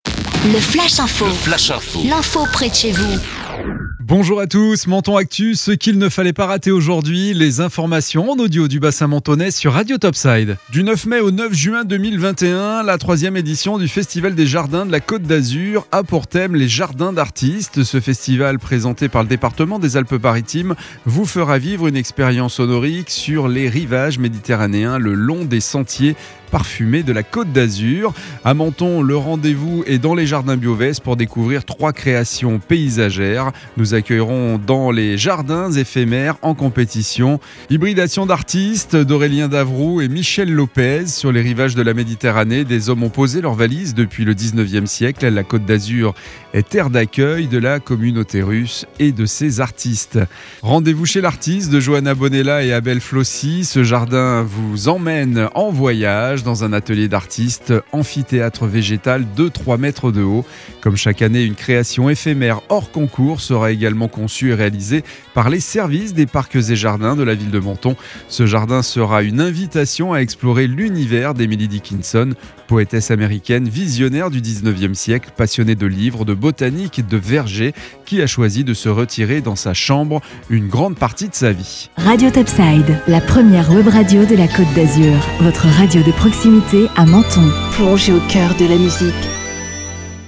Menton Actu - Le flash info du dimanche 9 mai 2021